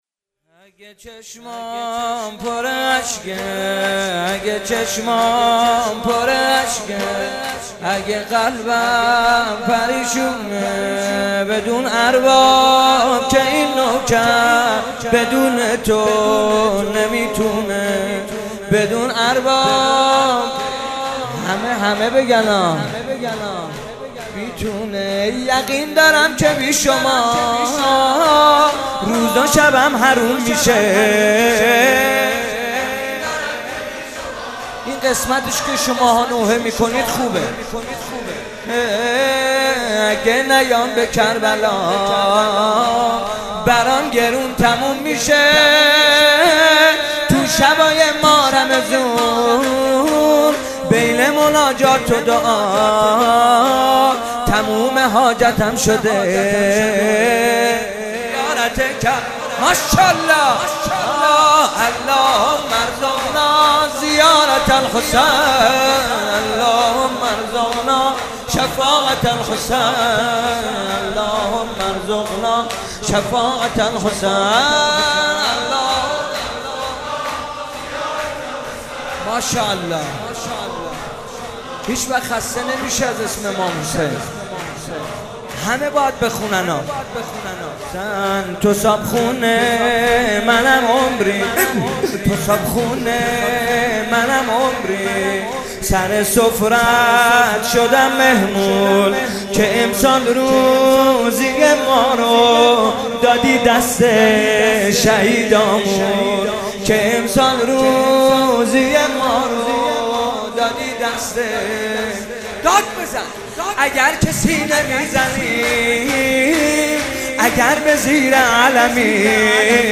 مداحی های شب بیست و ششم ماه مبارک رمضان در هیئت مکتب الزهرا(س)
شور/اگه چشمام پراشکه